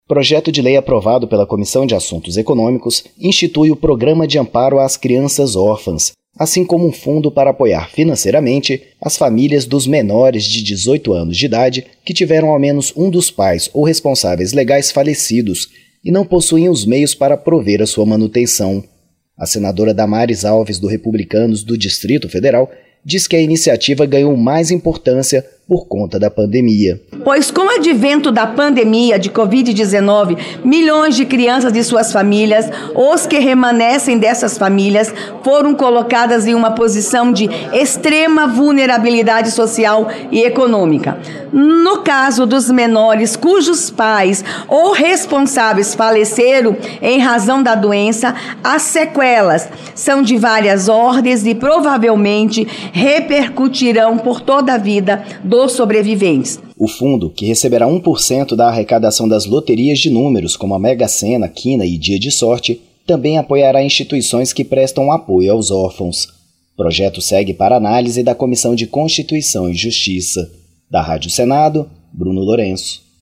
Relatora, Damares Alves (Republicanos-DF) diz que iniciativa ganhou mais importância por conta da pandemia.